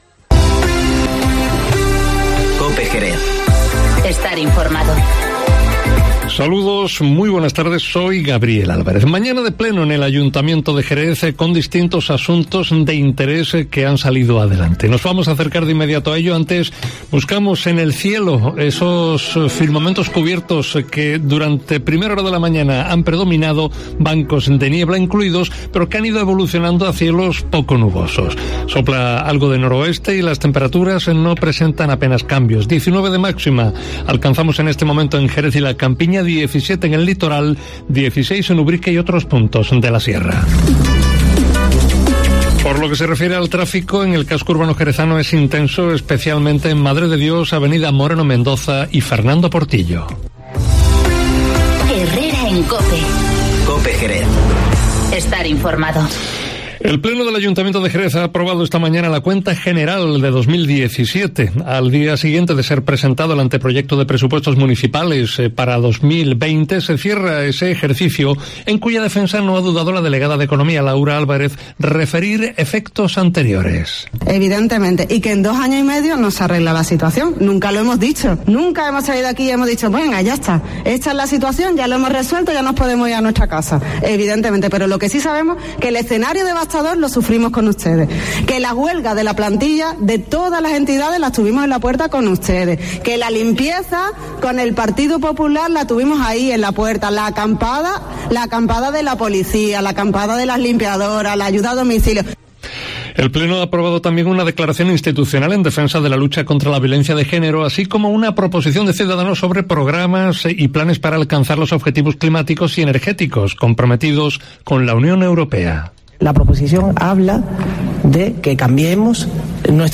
Informativo Mediodía COPE en Jerez 28-11-19